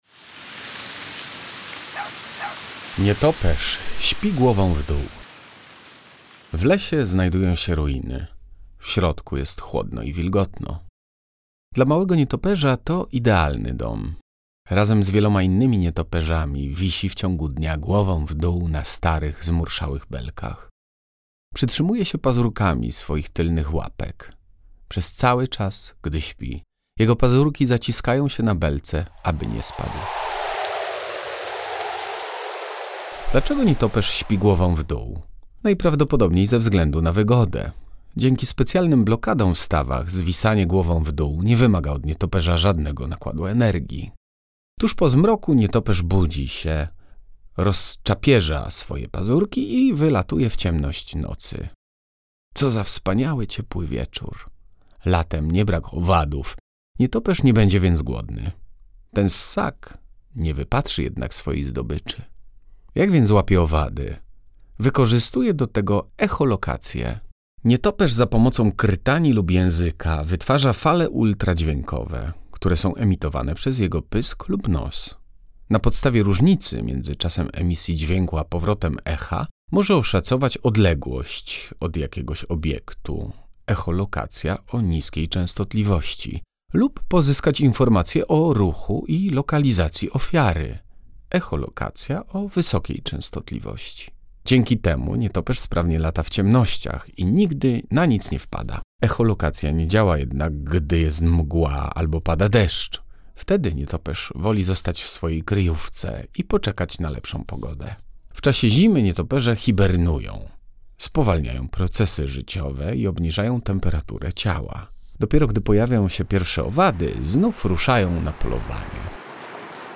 Na dołączonej do książki płycie CD i pod kodem QR znajduje się interesująco opowiedziana historia, której towarzyszą odgłosy przyrody.
8_Nietoperz_pi_gow_w_d.mp3